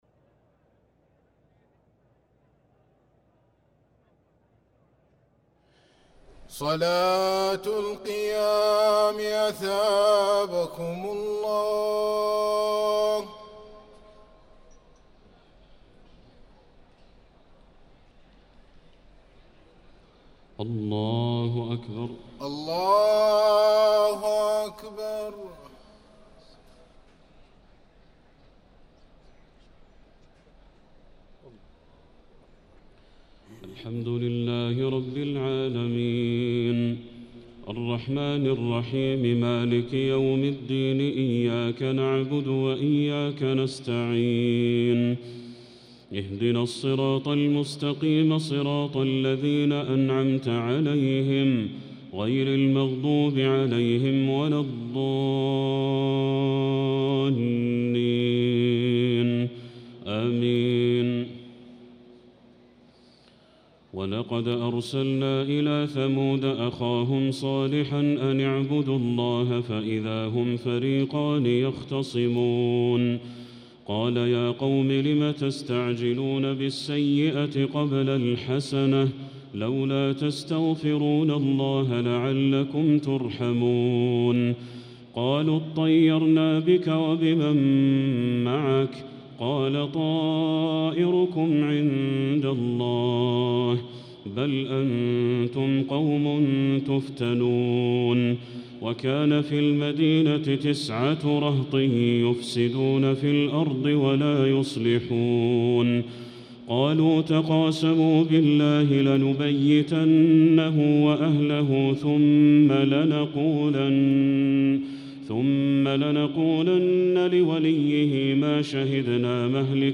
صلاة التراويح ليلة 23 رمضان 1445